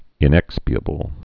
(ĭn-ĕkspē-ə-bəl)